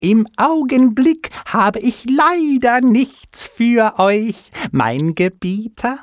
Händler: